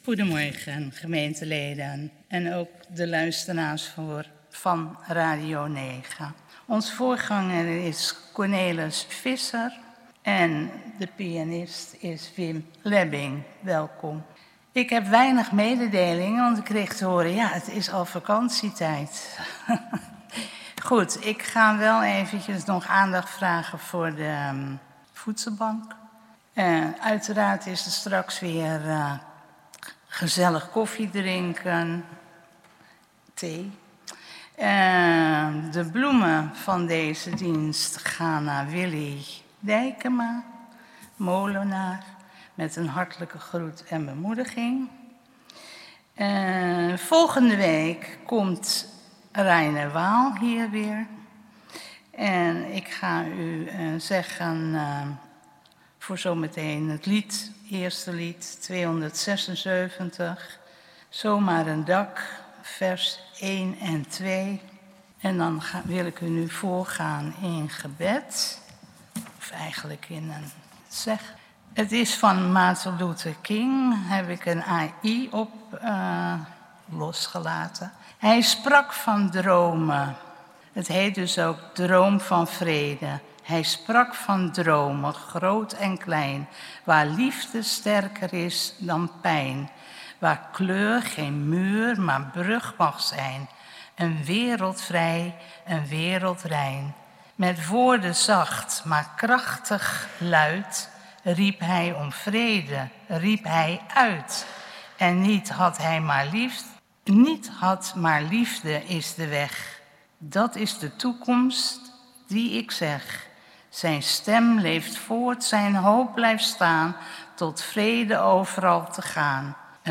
Protestantse Gemeente Oostzaan - Zondag 10.00 uur Kerkdienst in de Grote Kerk!
Kerkdienst